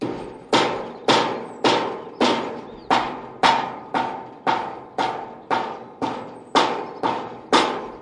冲击、撞击、摩擦 工具 " 钢板撞击
Tag: 工具 工具 崩溃 砰的一声 塑料 摩擦 金属 冲击